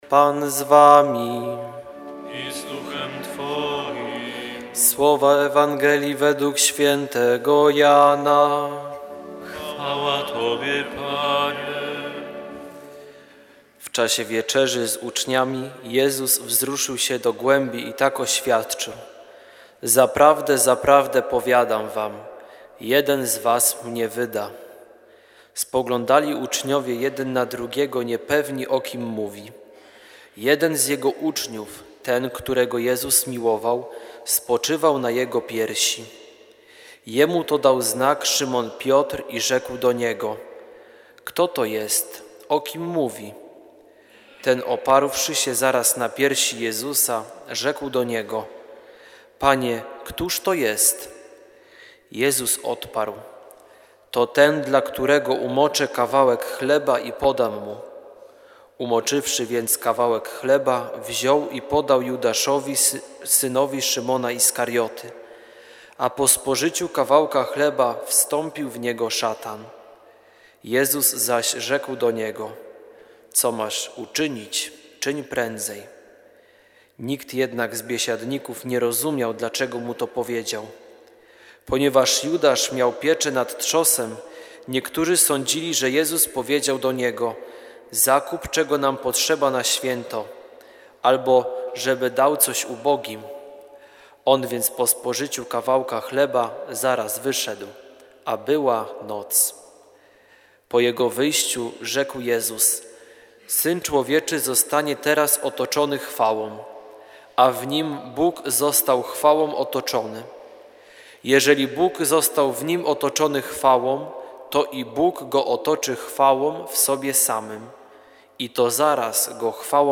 Nauka rekolekcyjna – Wielki Wtorek (22.03.2016):
REKOLEKCJE OSTATNIEJ GODZINY W te dni msza św. o godz. 20.00 z nauką rekolekcyjną.